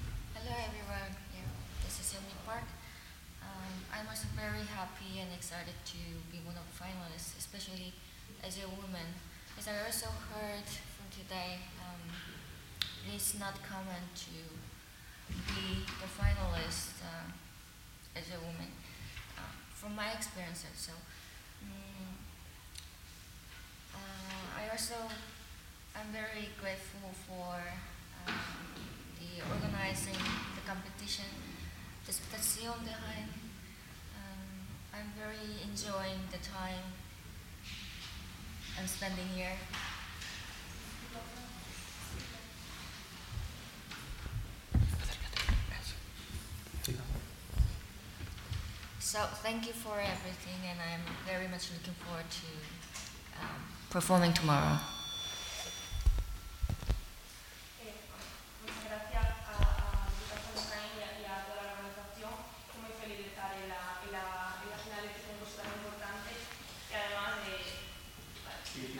Declaraciones en audio de los finalistas